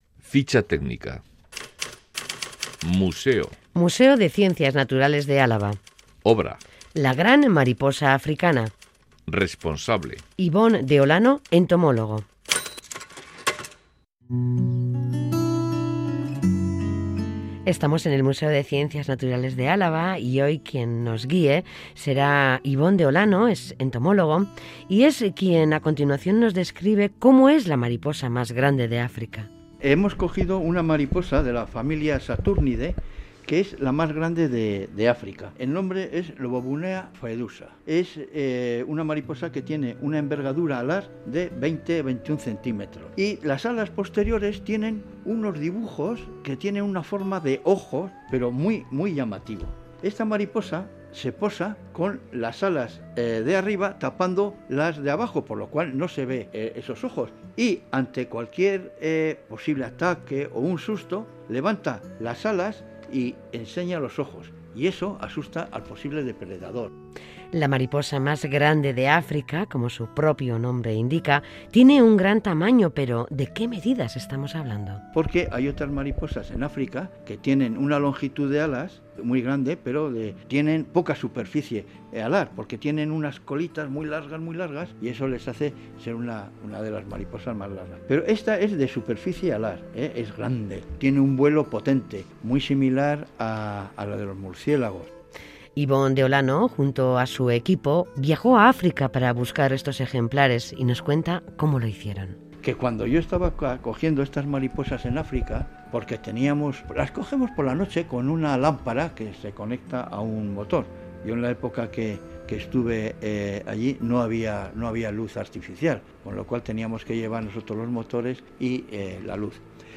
nos cuenta en el reportaje cómo realizaron dicha caza de mariposas y nos describe cómo es.